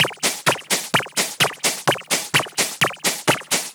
VEH1 Fx Loops 128 BPM
VEH1 FX Loop - 36.wav